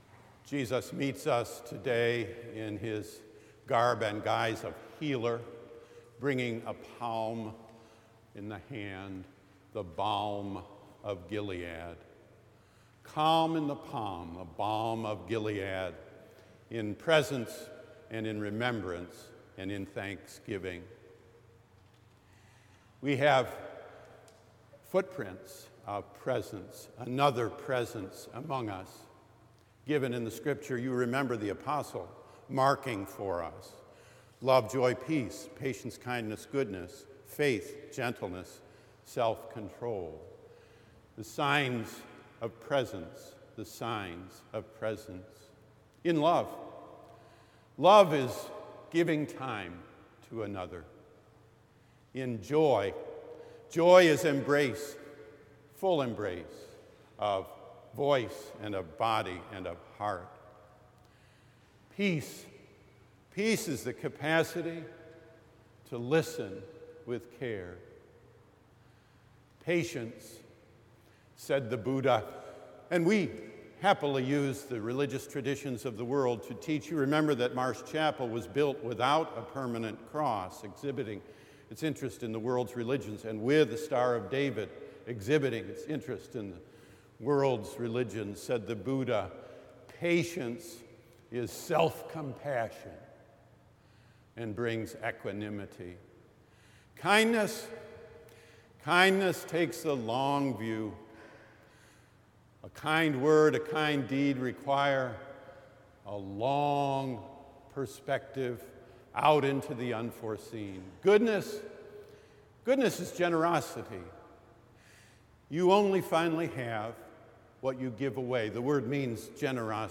Click here to hear the full service